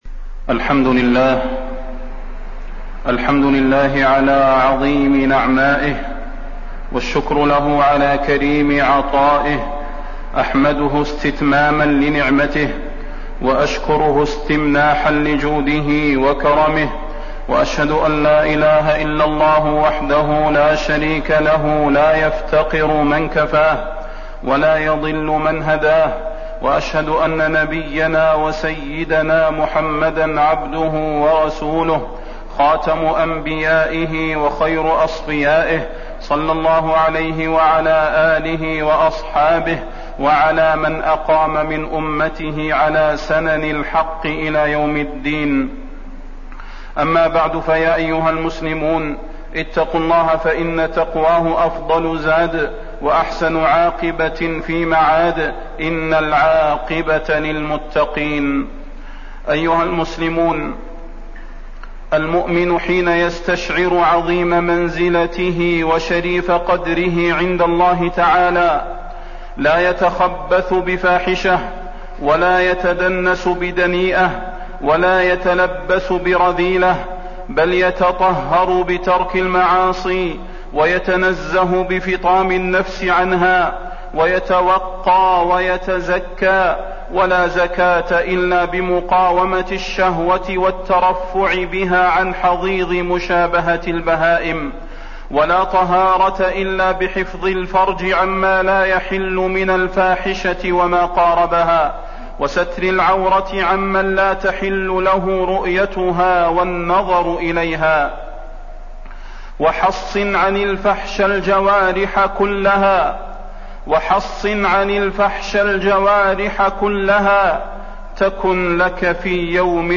فضيلة الشيخ د. صلاح بن محمد البدير
تاريخ النشر ٩ شعبان ١٤٣٠ هـ المكان: المسجد النبوي الشيخ: فضيلة الشيخ د. صلاح بن محمد البدير فضيلة الشيخ د. صلاح بن محمد البدير التحذير من الفاحشة وأسبابها